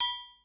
Perc_14.wav